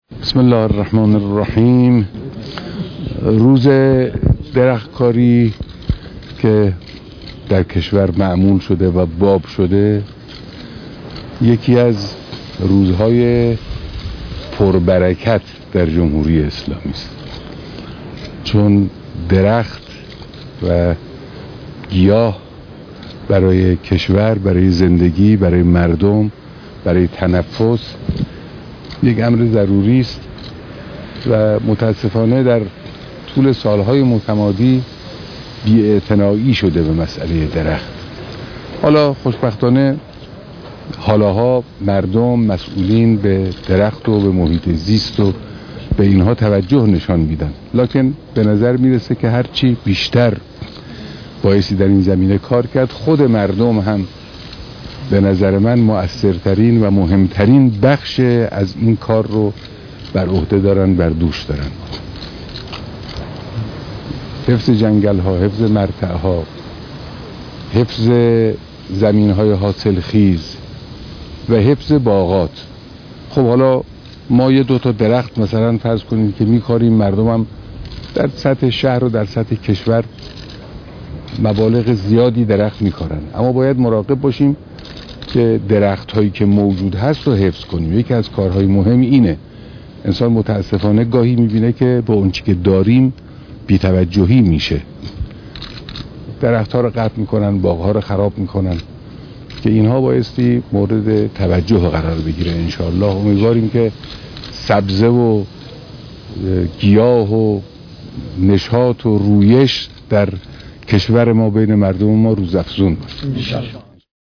بیانات به مناسبت روز درختكارى‌